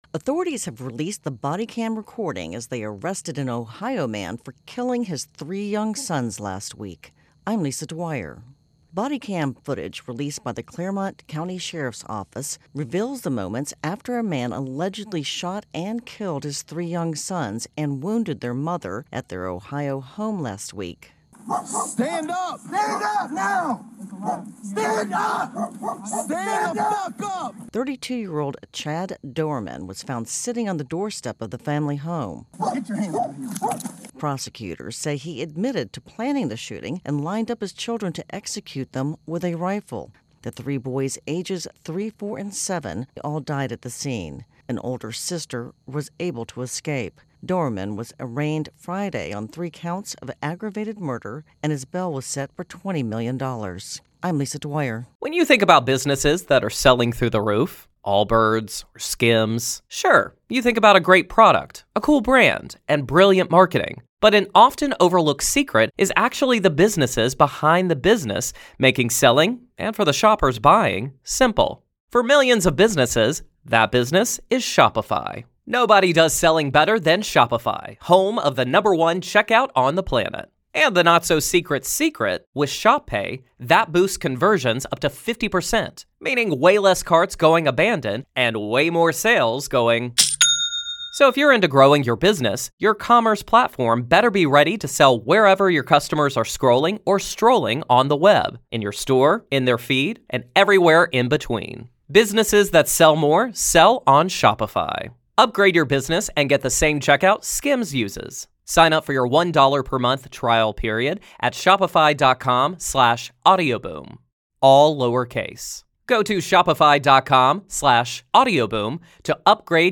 ((Body cam footage from June 15, 2023 event released)) COURTESY: Clermont County Sheriff's Office ((mandatory on-air credit))